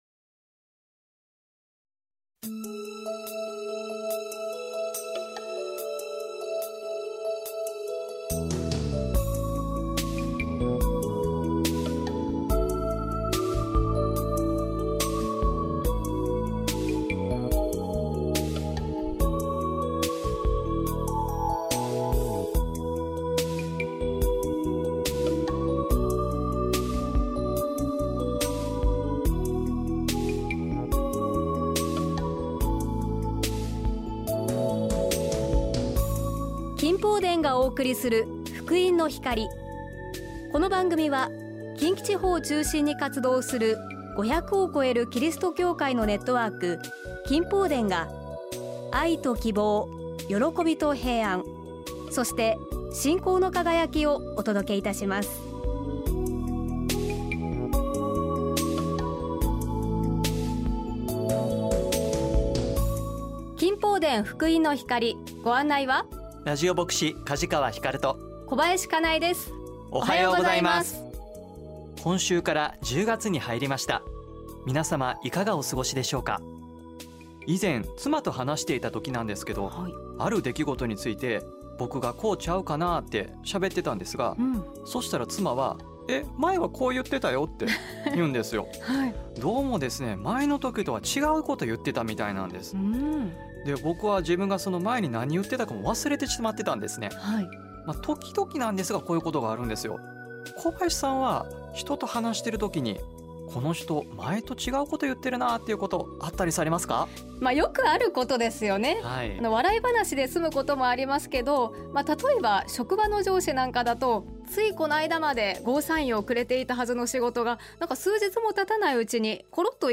御言葉とお話